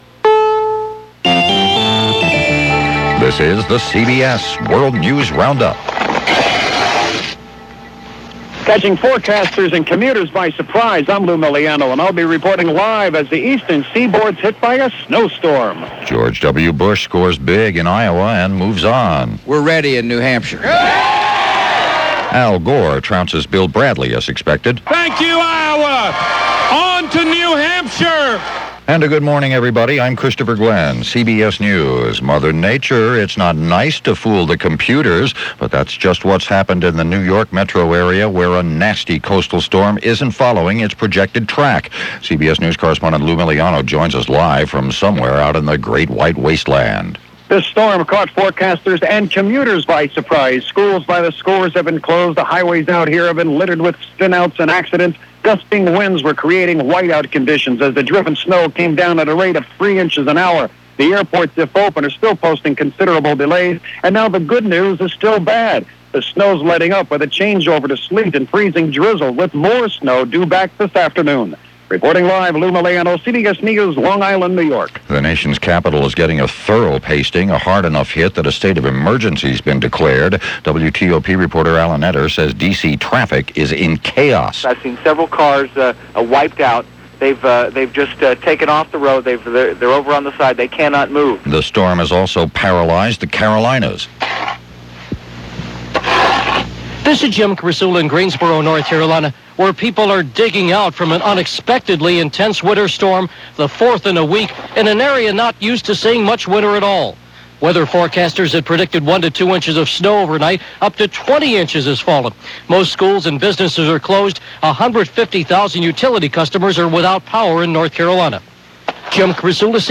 And that’s a small slice of what went on, this January 25, 2000 as presented by The CBS World News Roundup.